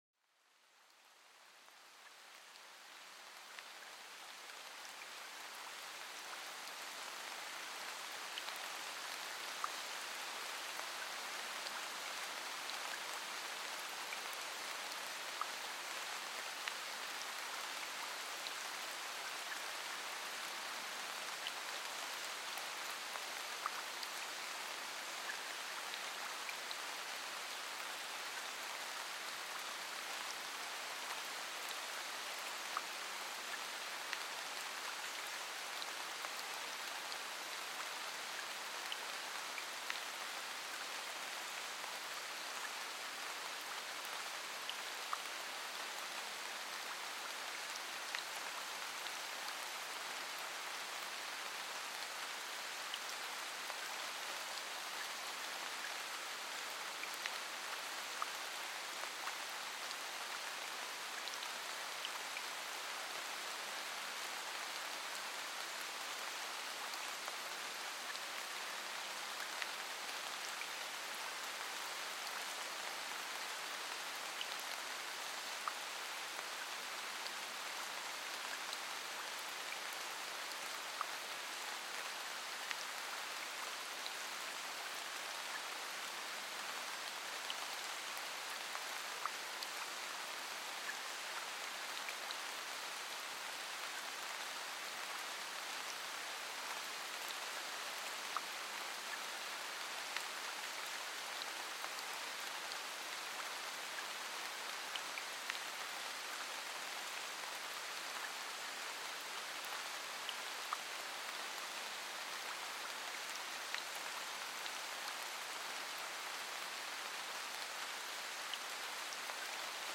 Plongez dans une forêt luxuriante où le doux crépitement de la pluie danse sur les feuilles et le sol moussu. Ces sons enveloppants recréent l’apaisement d’un refuge naturel, parfait pour calmer l’esprit et réduire le stress.